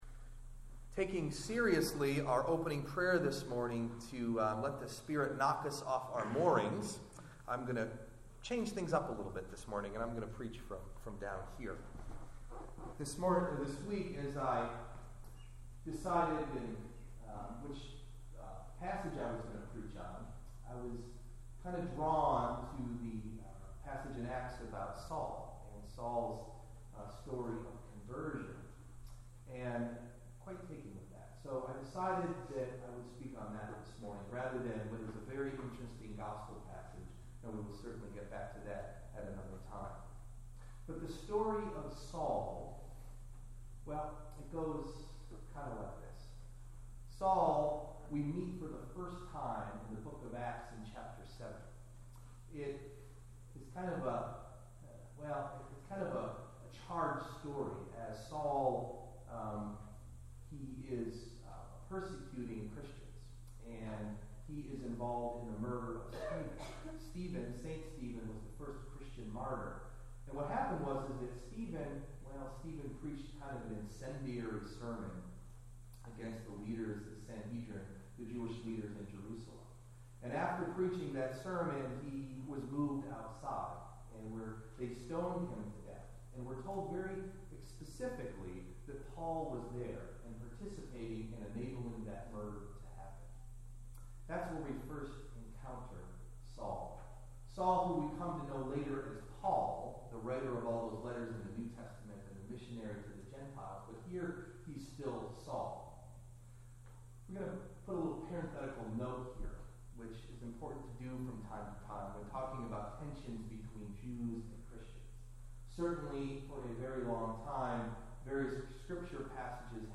Sermon: “Whose Story?”
Delivered at: The United Church of Underhill